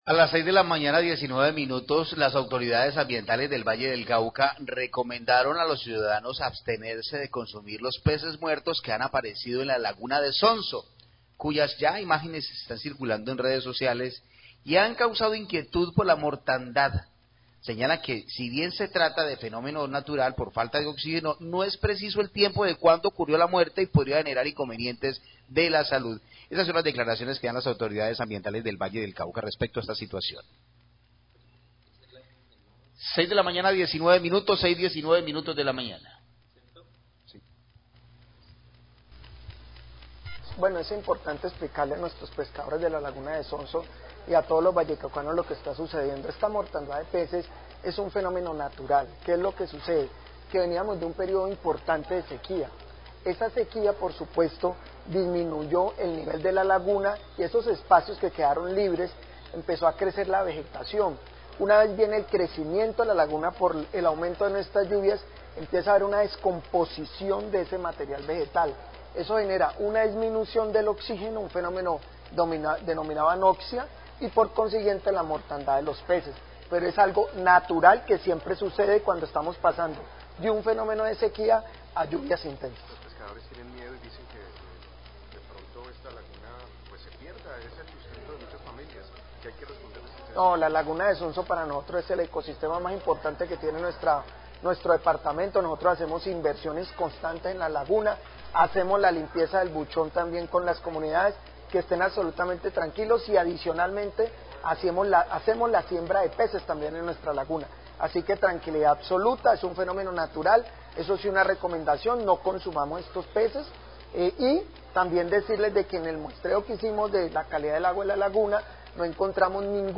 Informativo Radio Guadalajara